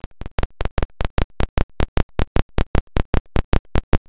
Clicking sound in AVI file - VideoHelp Forum
When I play an avi file, I hear only this clicking sound instead of the actual audio.
Sounds pretty messed up.